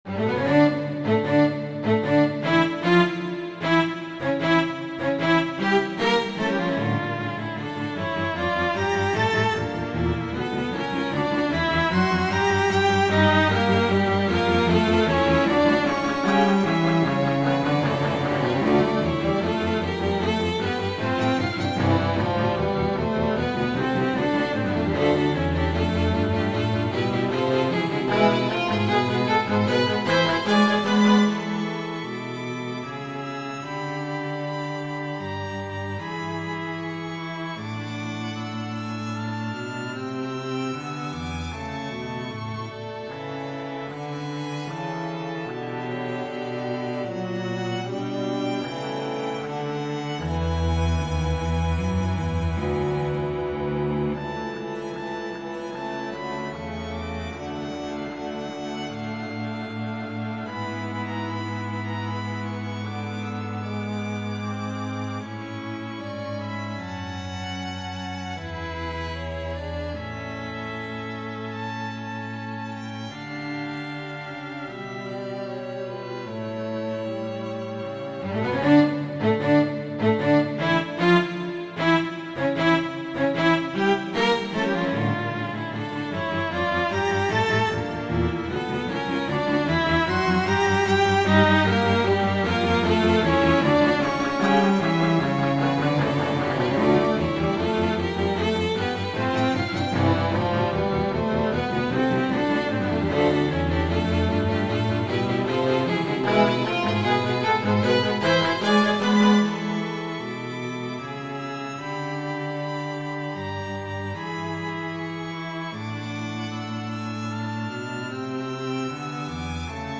I am trying to gain experience in writing for strings hence I decided to compose a string quartet.
Edit: I posted another audio with different soundfont and after correcting some issues.